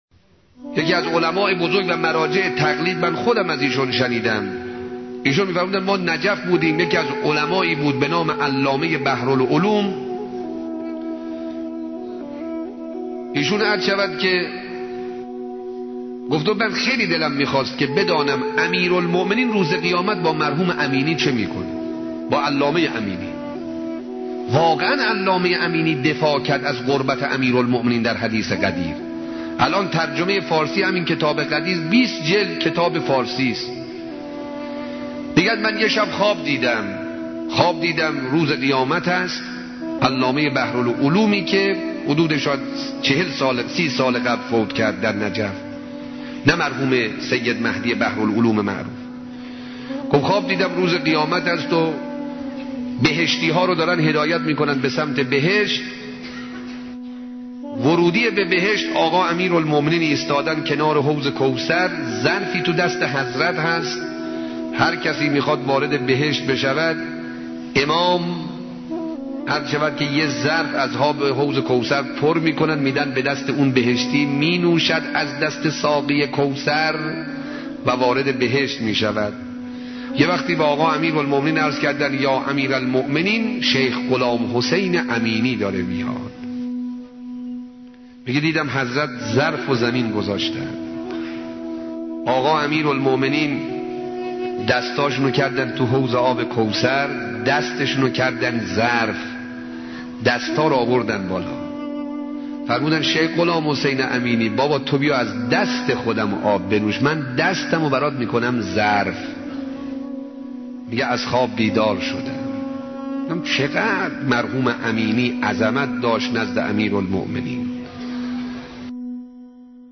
• علامه امینی, سبک زندگی, سخنرانی کوتاه